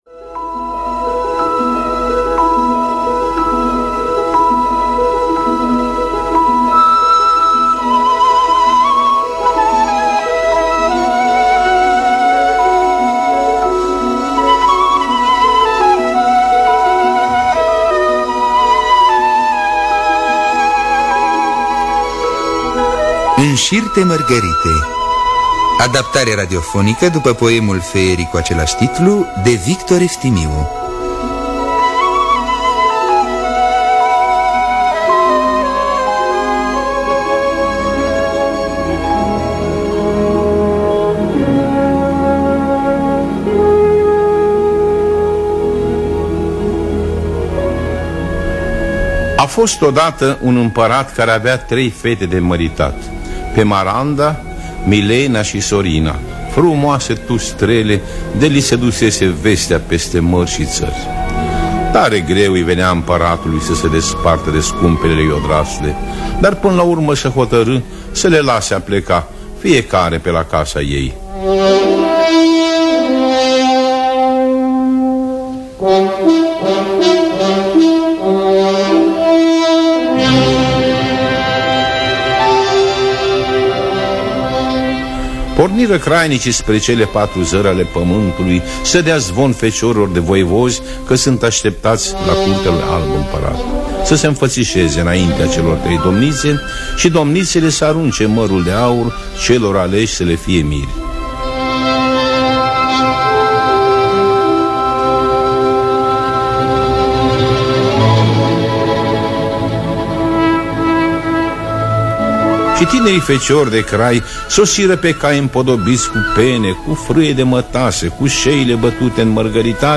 Înșir-te mărgărite de Victor Eftimiu – Teatru Radiofonic Online